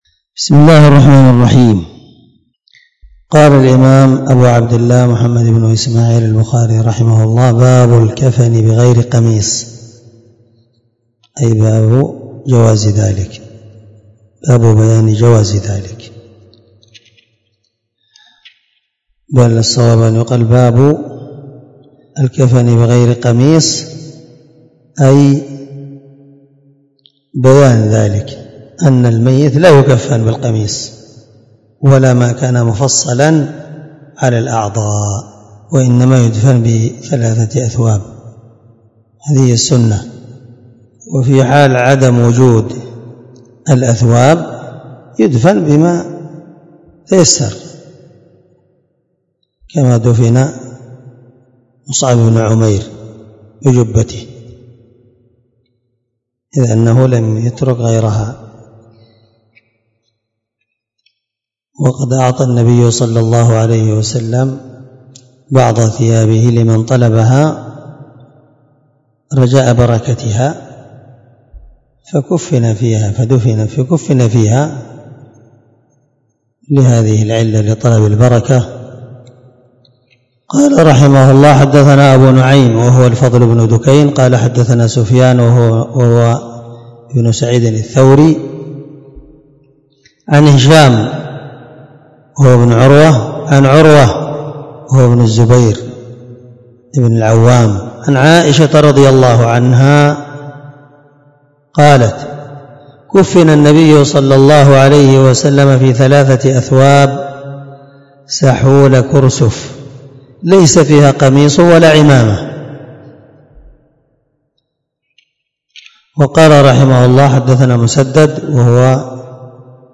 741الدرس 14من شرح كتاب الجنائز حديث رقم(1271-1274 )من صحيح البخاري